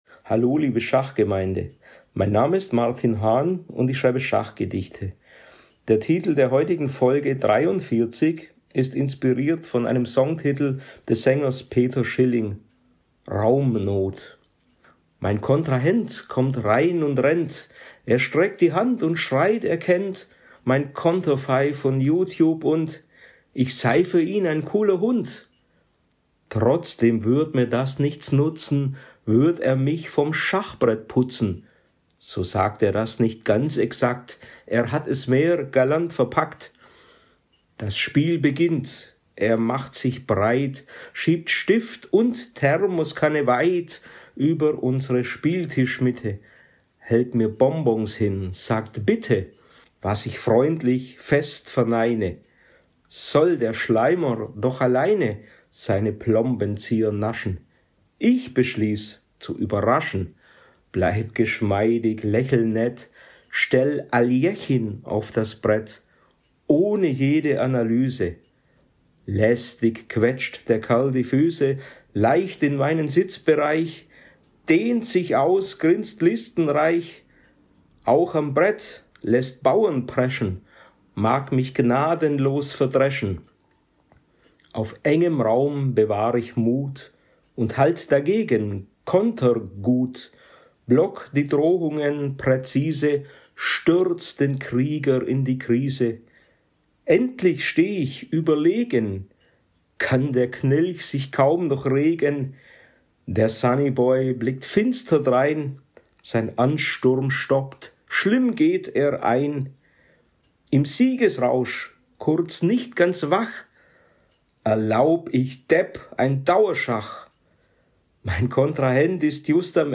Raumnot_-_Gedicht.mp3